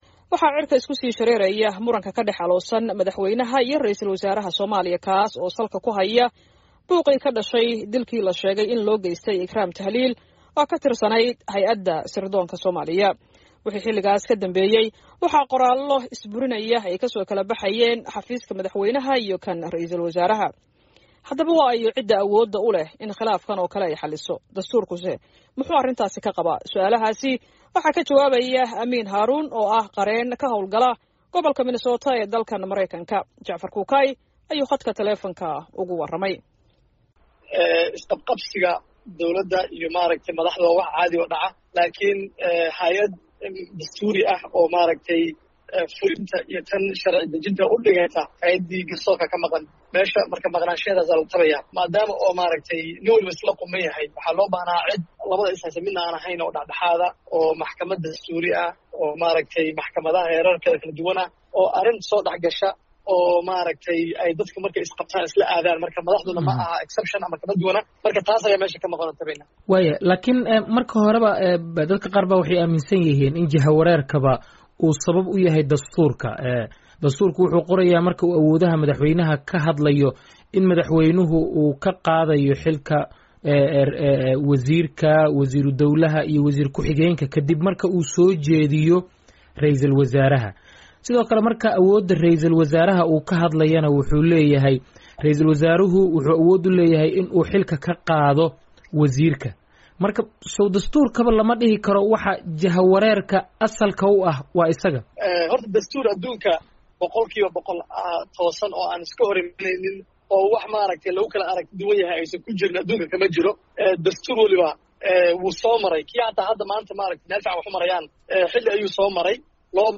khadka taleefoonka ugu warramay.